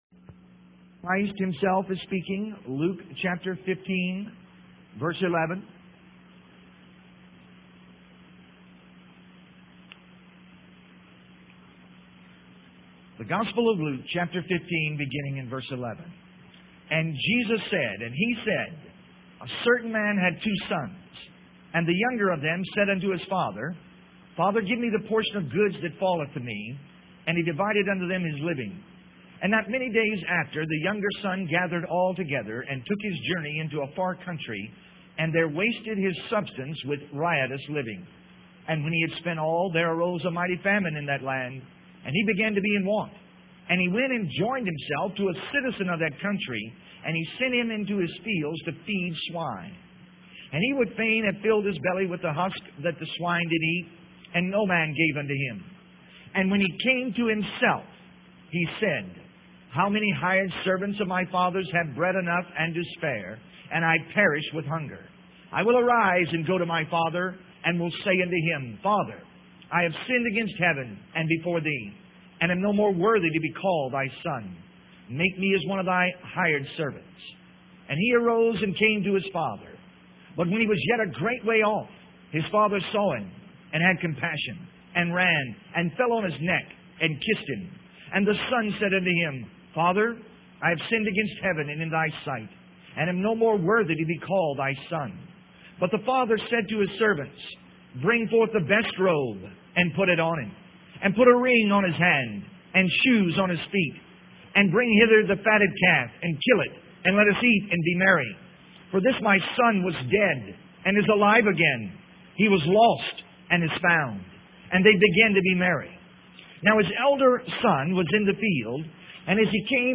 In this sermon, the preacher focuses on the parable of the prodigal son. He emphasizes the father's unconditional love and forgiveness towards his wayward son who had wasted his inheritance.